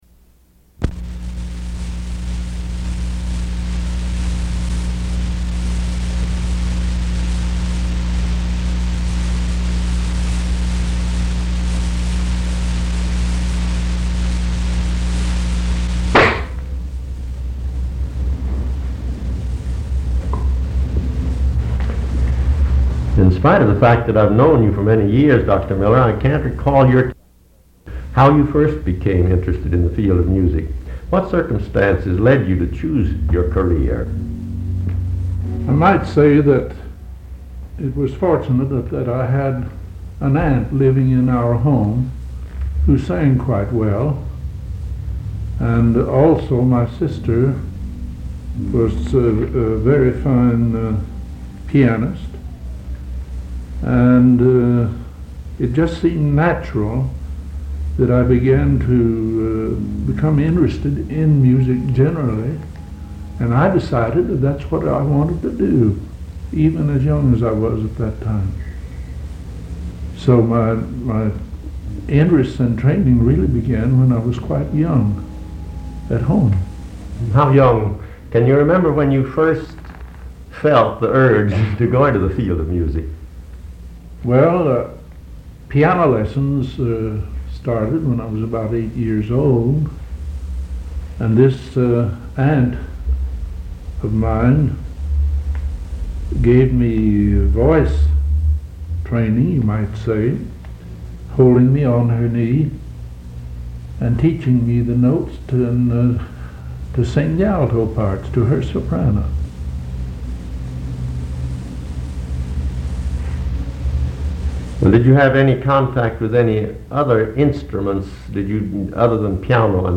Oral history interview